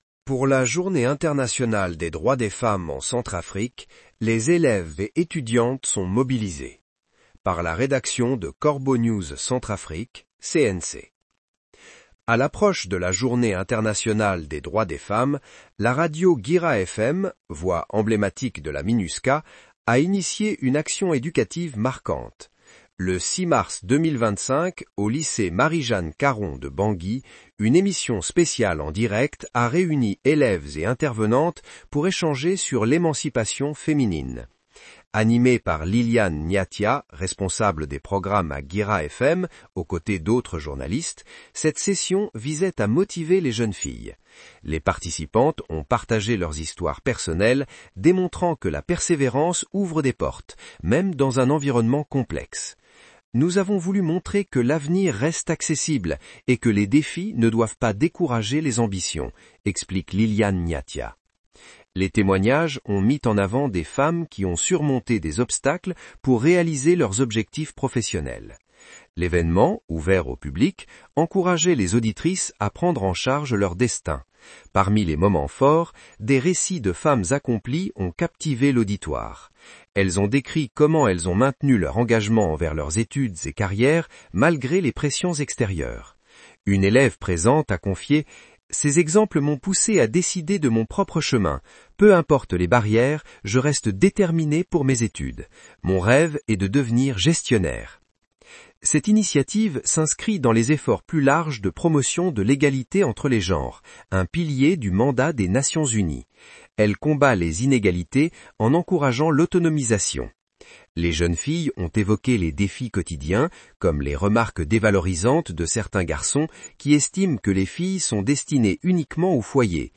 Le 6 mars 2025, au lycée Marie-Jeanne Caron de Bangui, une émission spéciale en direct a réuni élèves et intervenantes pour échanger sur l’émancipation féminine.